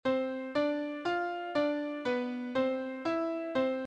• Retrograde plays the original melody backwards.
Melody C D F D B C E C.